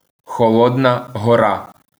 Аудиофайл с произношением.